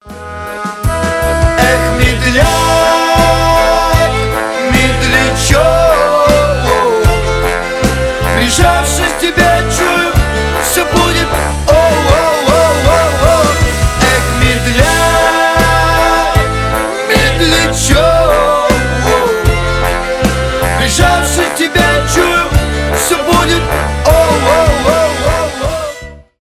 поп
медленные
фолк